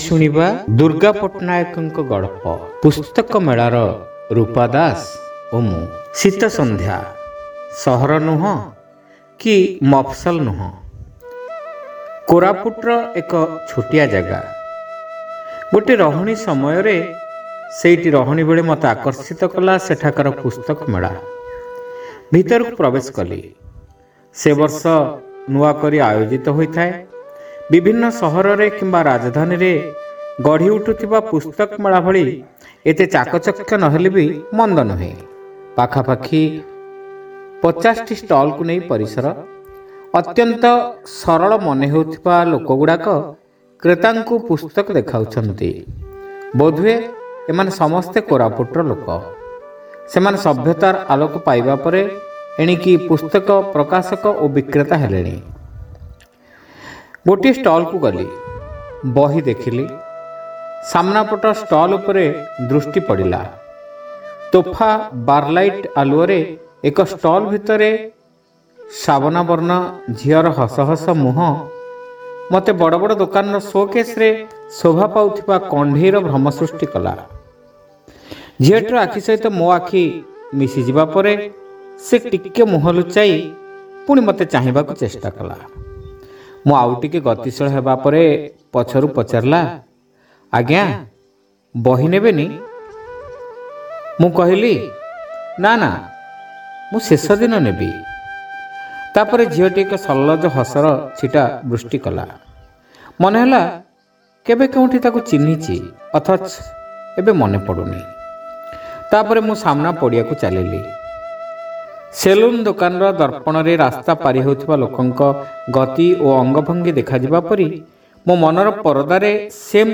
Audio Story : Pustaka Mela Ra Rupa Das O Mu (Part-1)